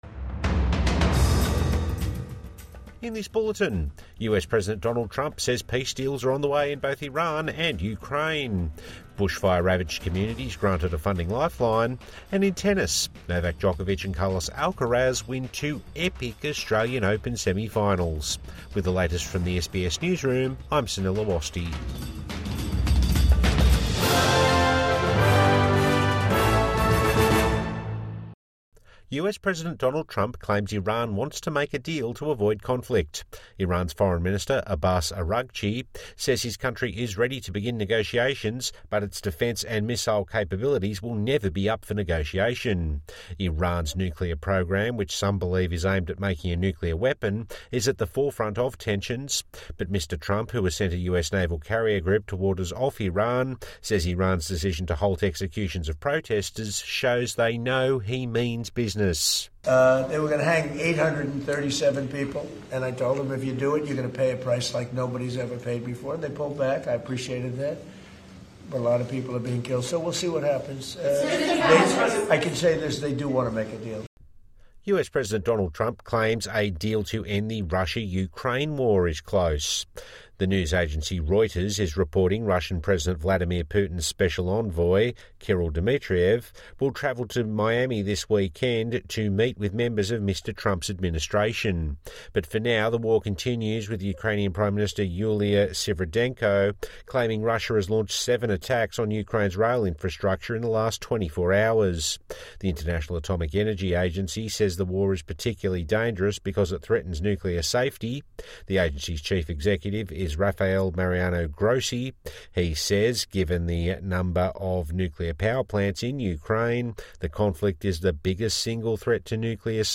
Trump claims peace deals are close in both Iran and Ukraine | Morning News Bulletin 31 January 2026